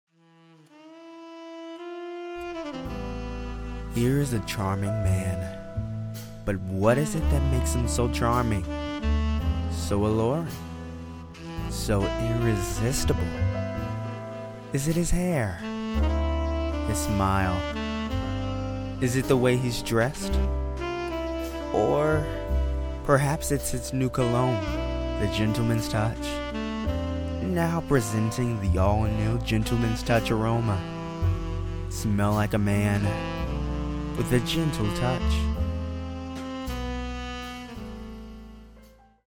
Commercial sample #2
Southern English, British English, General north American English , African American/Midwest
Teen
Young Adult